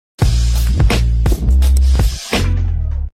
Wet Fart Sound Realistic Sound Button - Free Download & Play